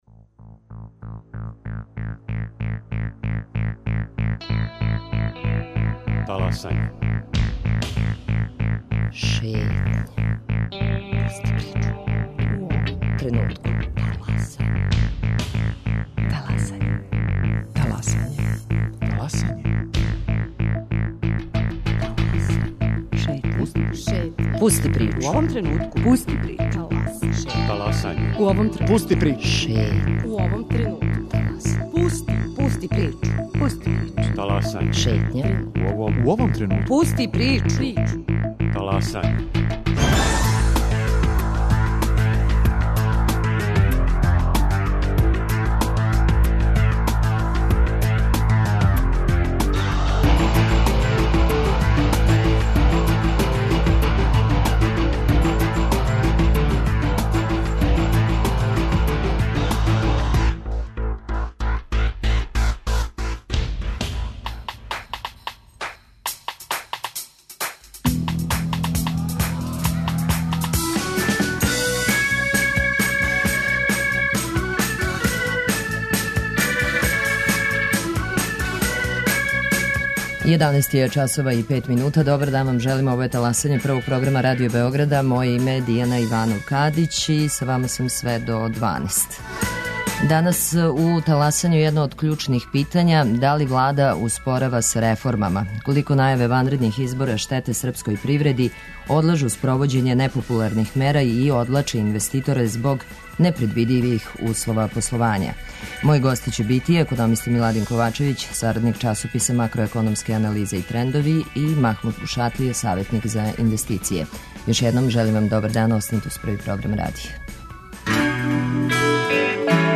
Гости: економиста